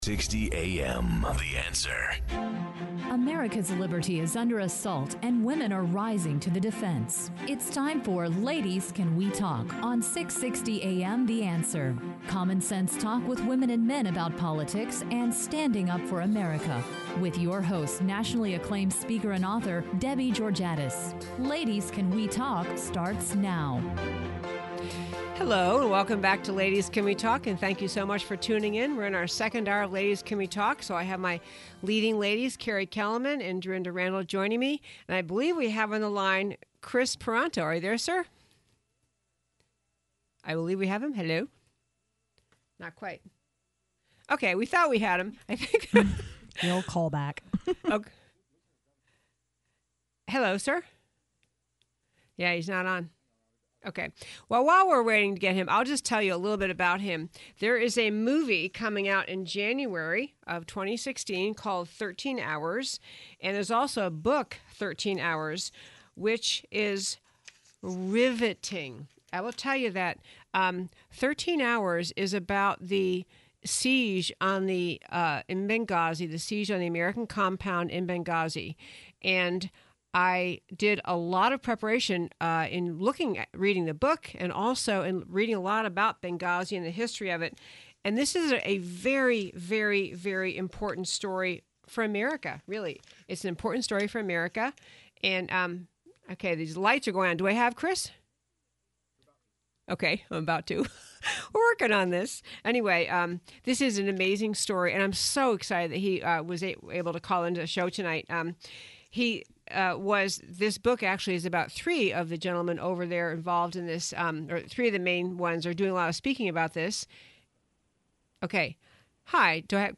Interview with Kris Paronto of “13 Hours” in Benghazi; the Oregon Murders; John Kasich’s Christianity
Listen to the podcast from the second hour of our October 11th show.
Kris (“Tonto”) Paronto—one of the security contractors on duty in Benghazi on September 11, 2012—calls in to talk about the experience that day (and night).
A compelling interview.